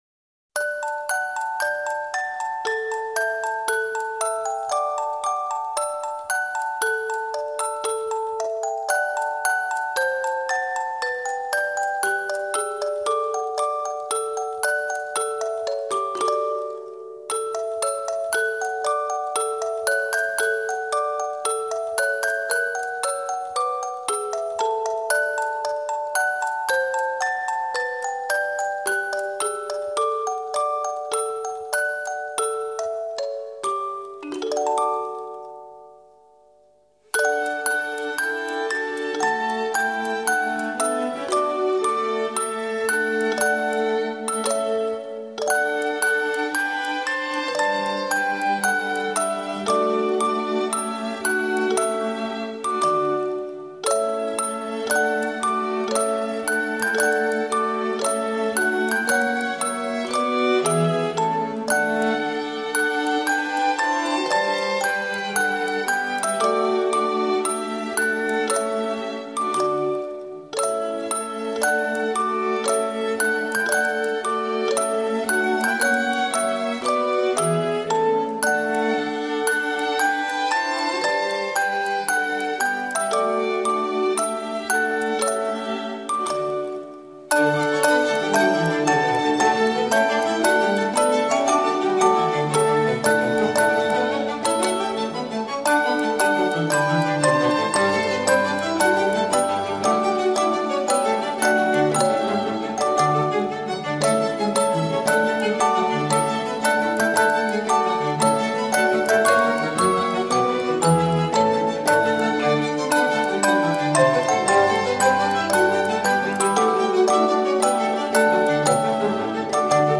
Нежная классика Музыка